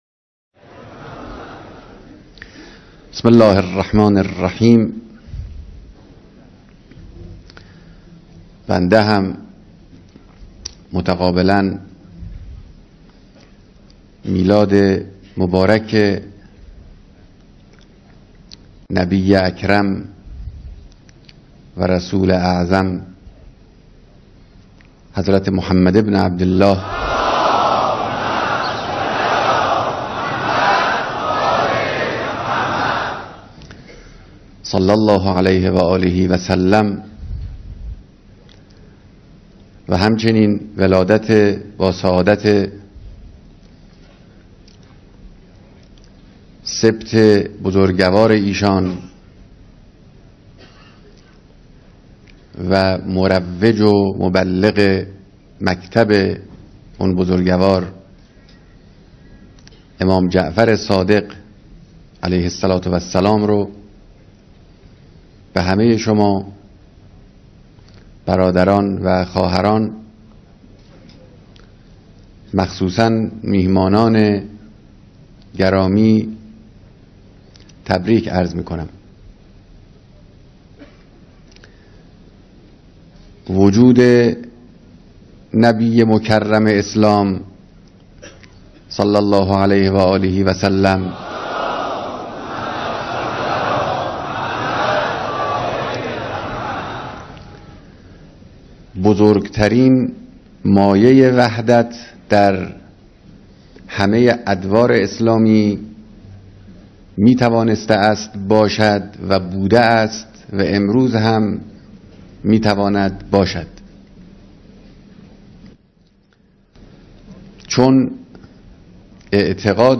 بیانات رهبر انقلاب در دیدار میهمانان شرکت‌کننده در کنفرانس وحدت اسلامی